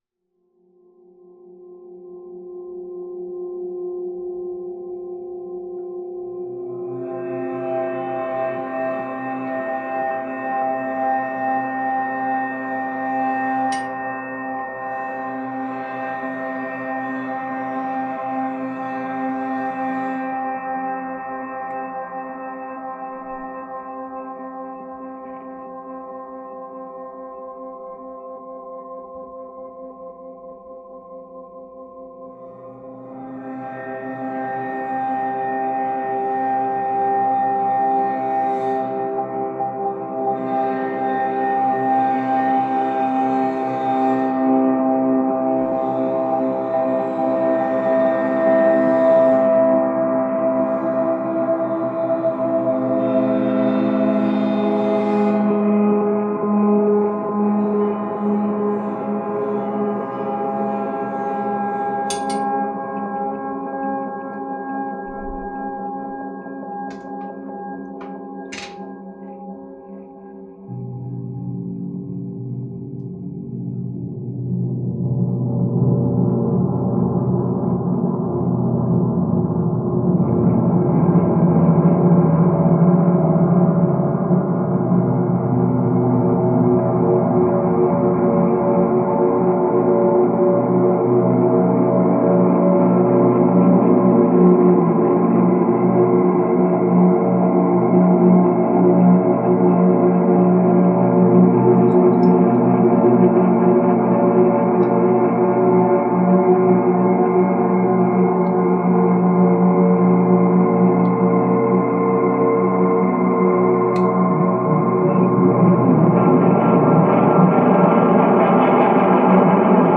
I'll leave the explode in the recording for kicks. the kind of sound that makes you afterwards not want to emit a single decibel for at least a couple days, in utter fear of your neighbor's wrath
okay, clip of gong through effects, sometimes bowed, sometimes "gonged" lightly but repeatedly, or just tapped. here is the clip *without* the screetching death sound of pain at the end
now, here is the same clip but with an extra ten seconds at the end. turn your volume down at 5:10
seriously, it sounds like the end of the world
killgong_died.mp3